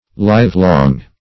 Livelong \Live"long`\, a. [For lifelong. Cf. Lifelong.]